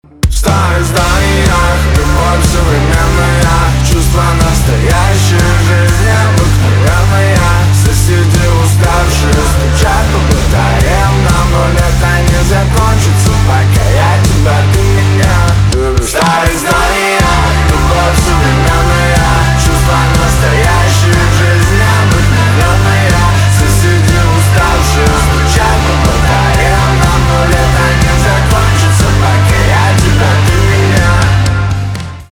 поп
чувственные
гитара , барабаны